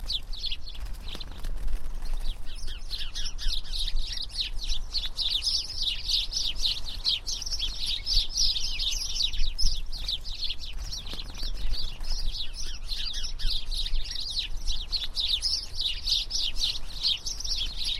Человек прогуливается в парке среди кустов